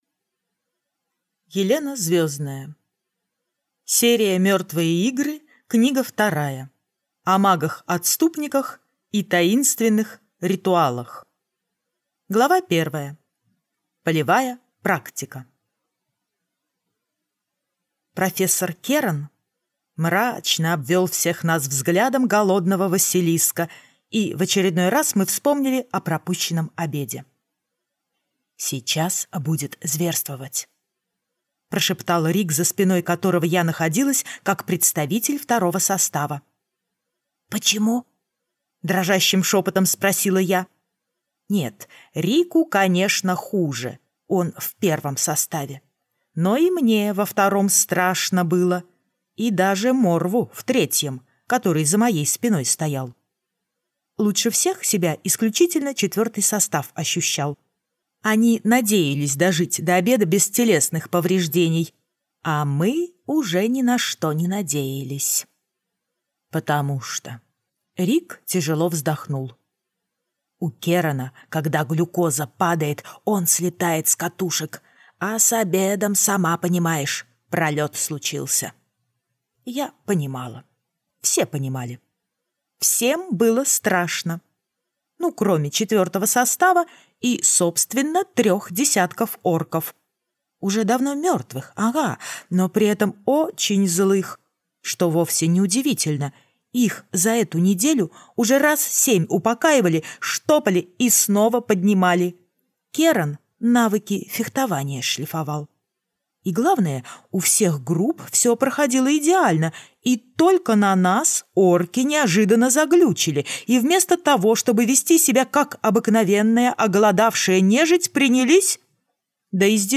Аудиокнига Мертвые игры. Книга вторая. О магах-отступниках и таинственных ритуалах - купить, скачать и слушать онлайн | КнигоПоиск